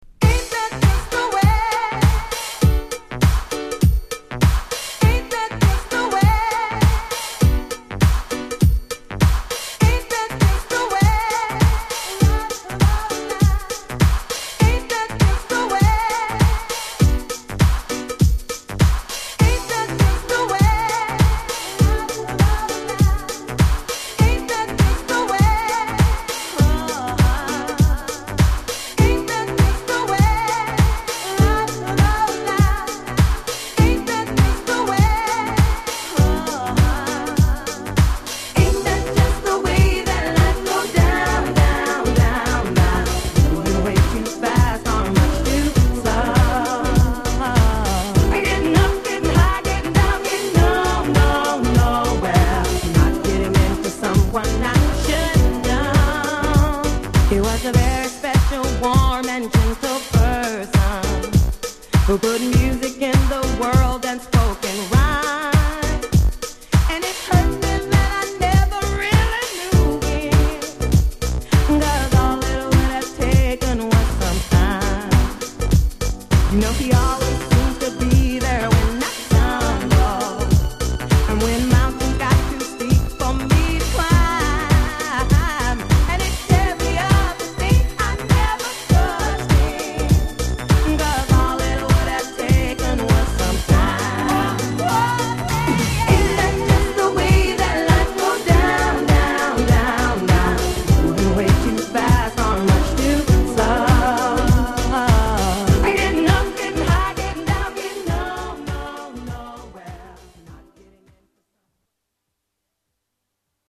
Genre: #R&B
Sub Genre: #1990s